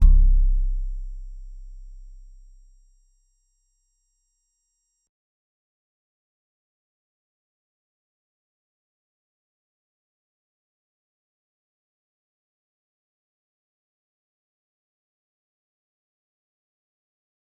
G_Musicbox-C1-mf.wav